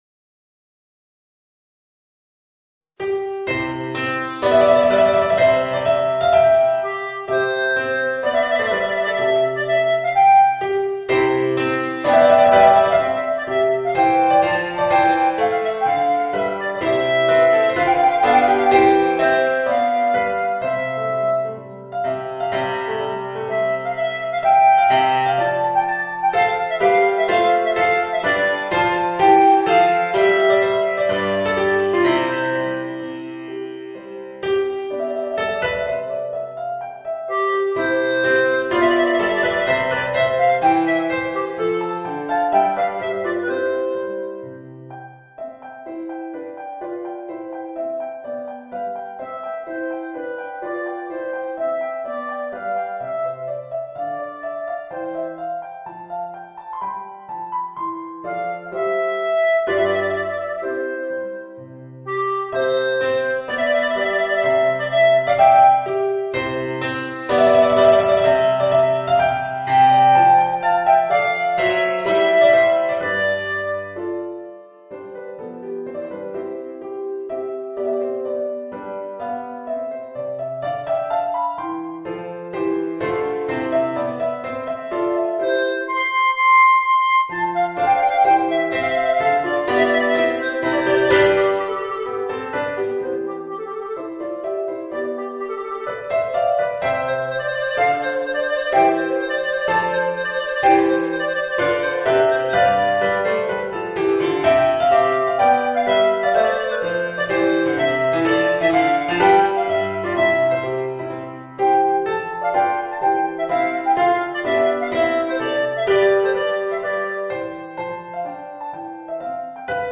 Voicing: Clarinet and Organ